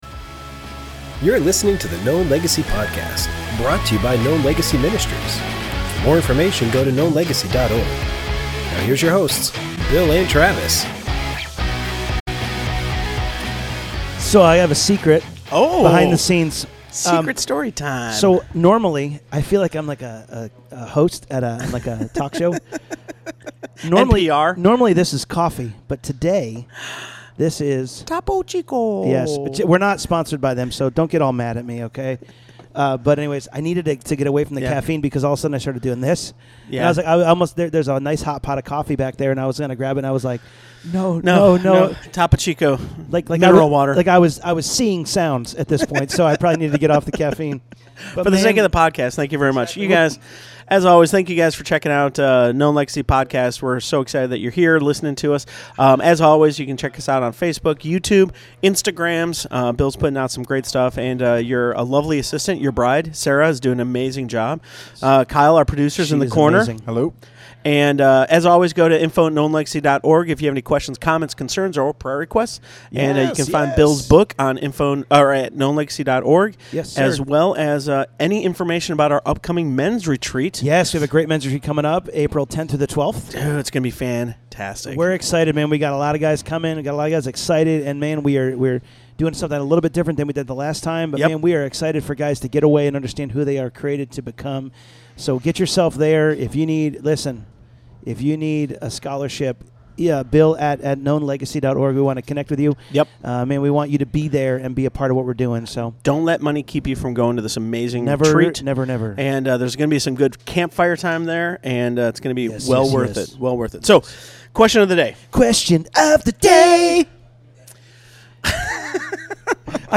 Making Marriage Easier - Interview